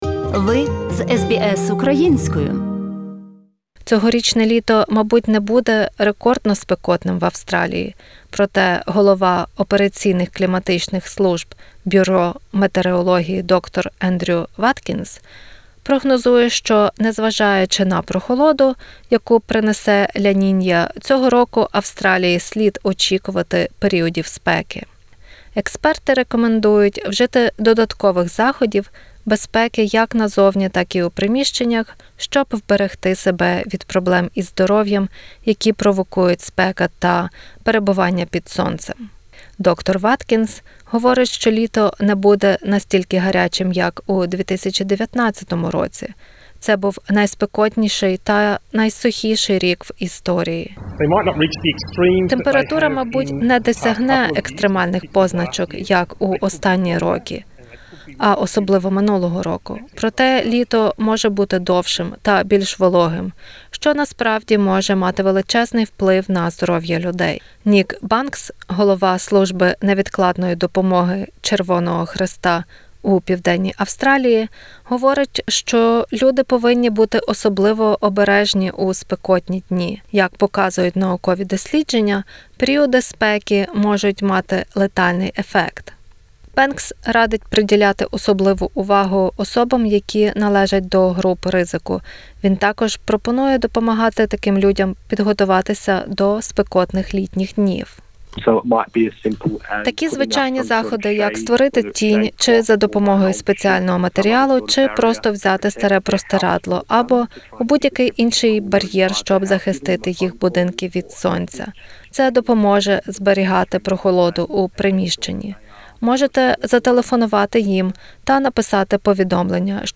Наш подкаст розповідає про те, що потрібно робити щоб запобігти тепловому удару і як надавати першу допомогу при перегріві. Також почуєте поради експертів про те як захистити шкіру від спекотного австралійського сонця.